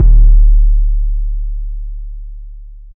Block808_YC.wav